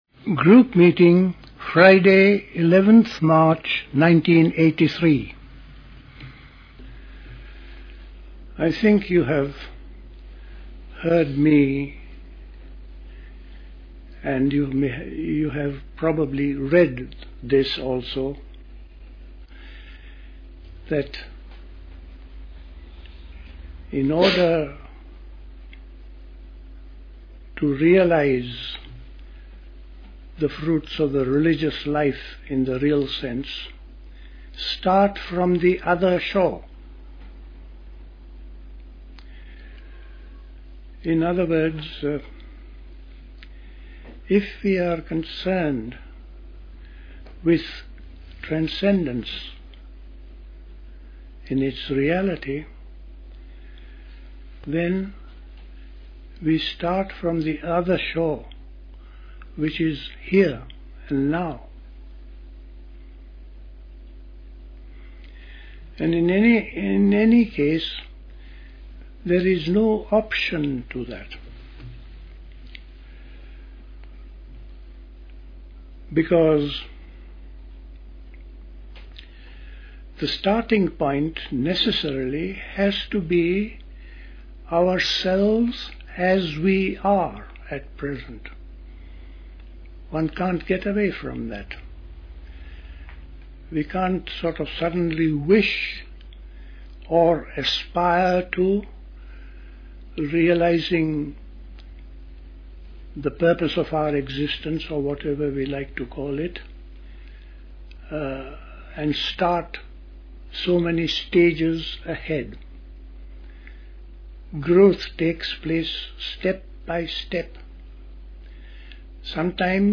A talk
at Dilkusha, Forest Hill, London on 11th March 1983